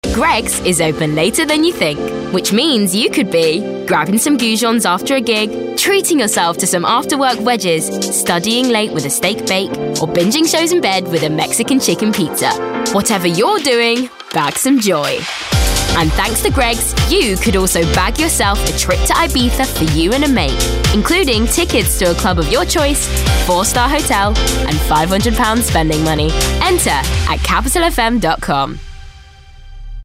London/Neutral, Upbeat/Natural/Youthful